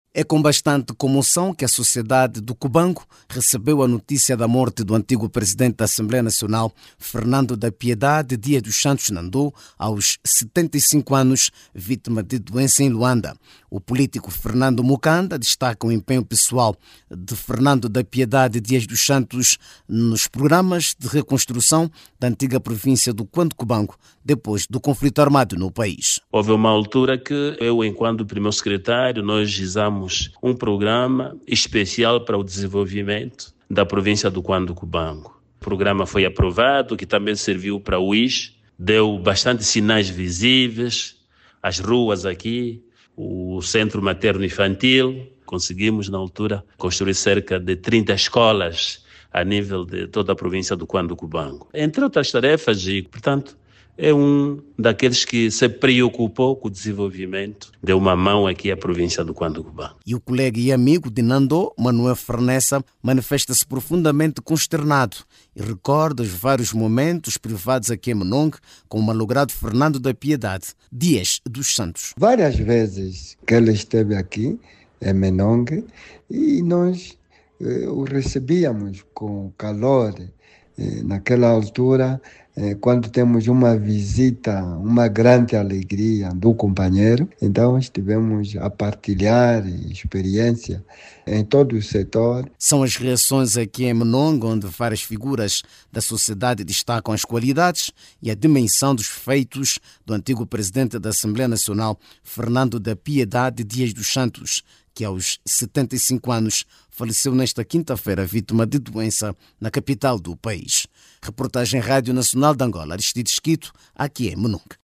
Em Menongue, amigos e companheiros afirmam que Nandó esteve sempre preocupado com o desenvolvimento da antiga província do Cuando Cubango, tendo contribuído para a construção de infraestruturas importantes como estradas, escolas e hospitais.